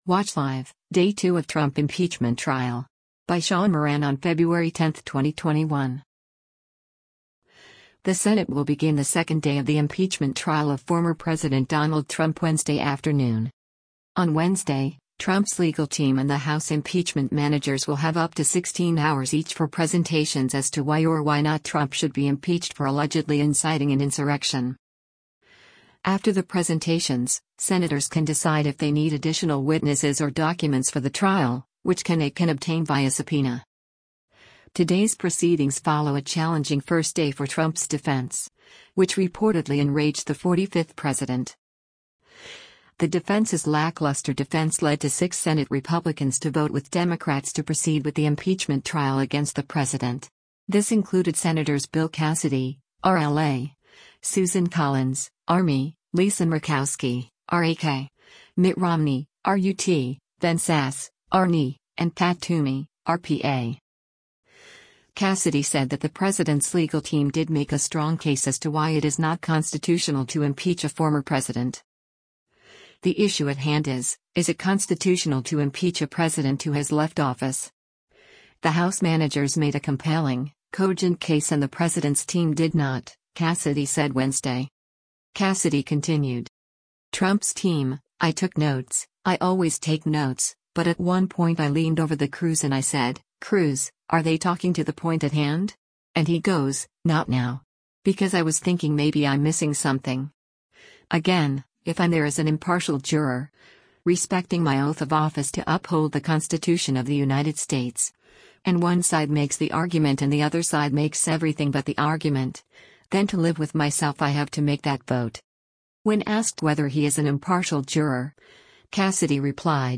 Video Source: Senate Floor and Thumbnail Image: Getty Images